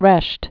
(rĕsht)